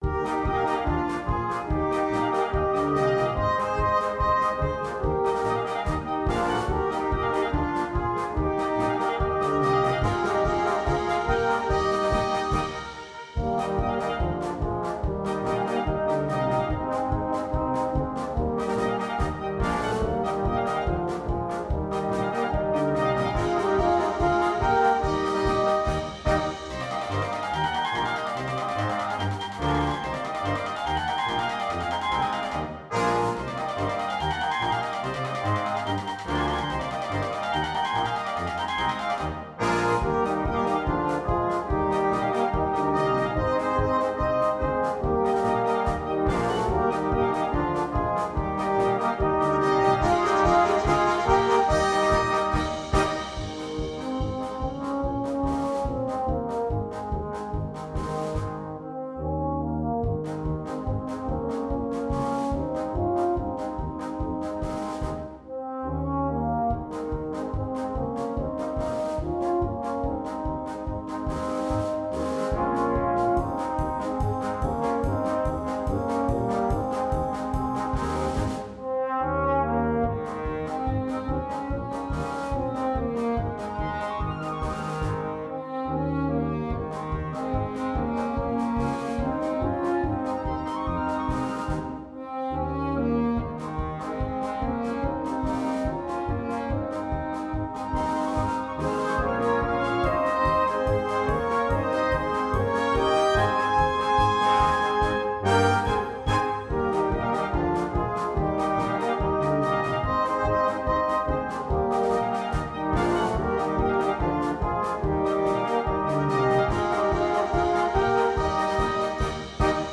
2. Blaskapelle
ohne Soloinstrument
Polka